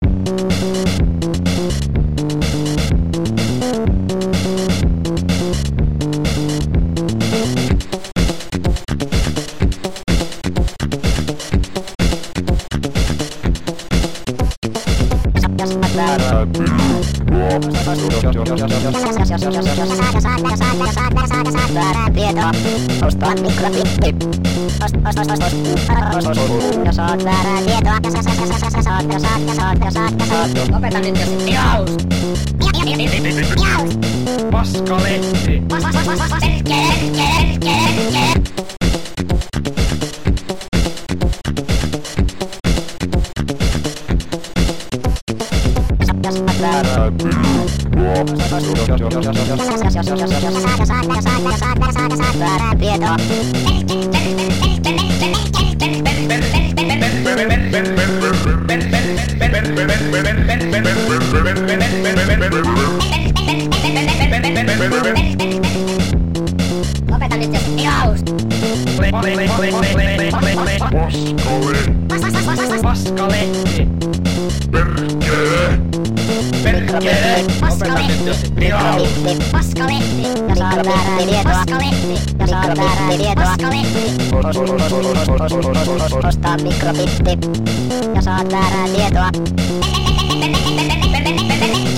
Oijoi, nyt löytyi pieni helmi 1990-luvulta, nelikanavainen Amiga-moddi mod.alf-toimii.